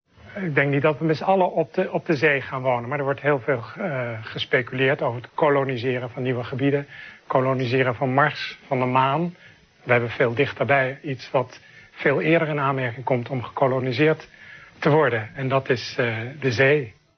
gesproken commentaar